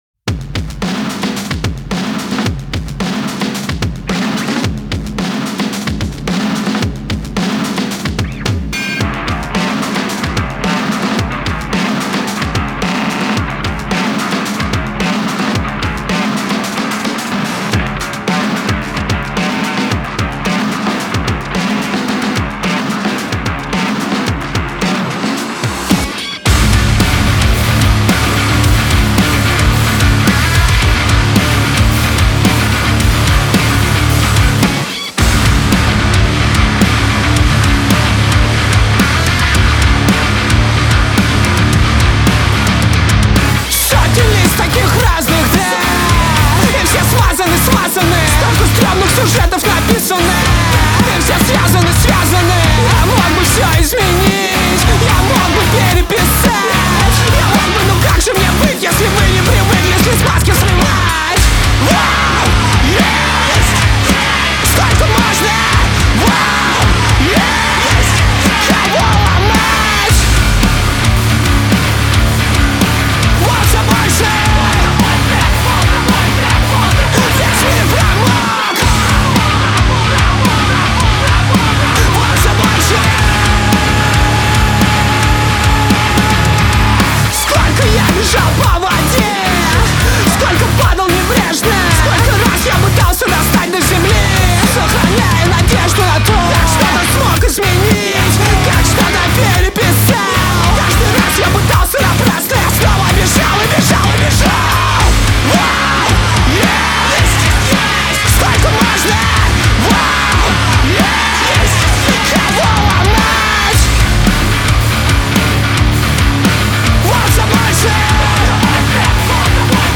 Зацените сведение rapcore
Пожалуйста, напишите, какие проблемы в миксе вам в первую очередь бросаются в уши.